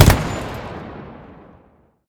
gun-turret-shot-6.ogg